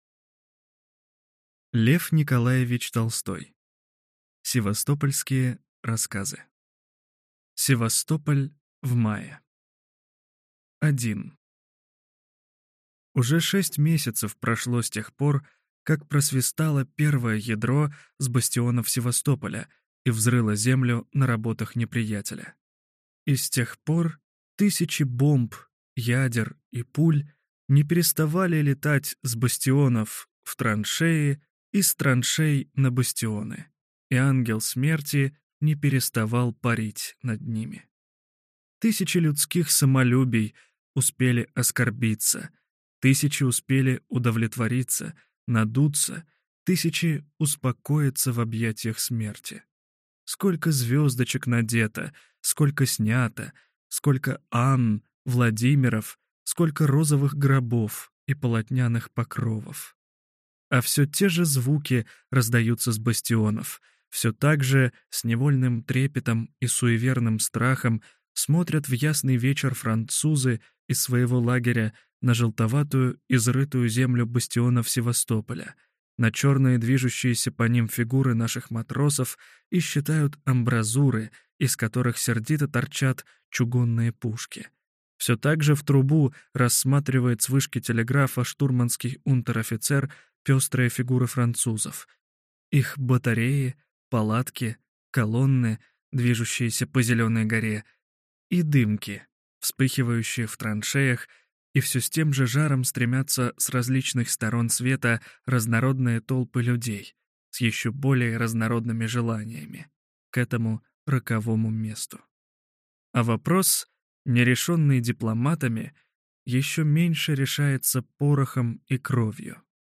Аудиокнига Севастополь в мае | Библиотека аудиокниг